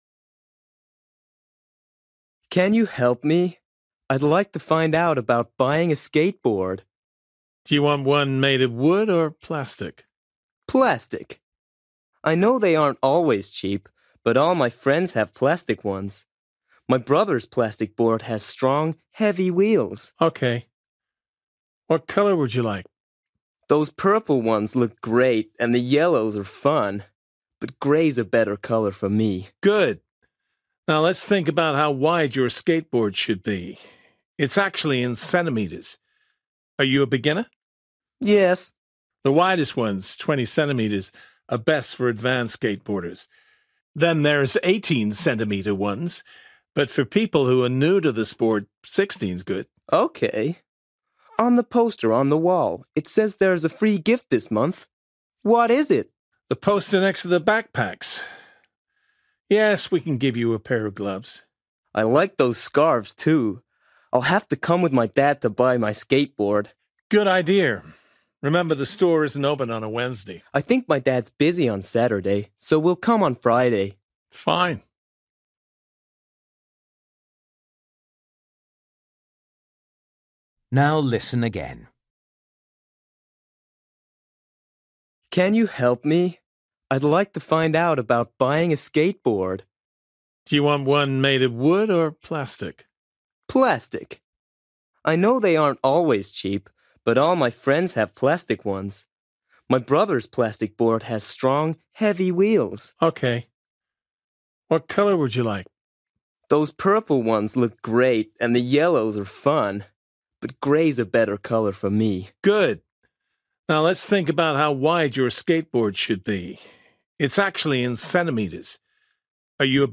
Bài tập trắc nghiệm luyện nghe tiếng Anh trình độ sơ trung cấp – Nghe một cuộc trò chuyện dài phần 5
You will hear Dan talking to a shop assistant in a sports shop.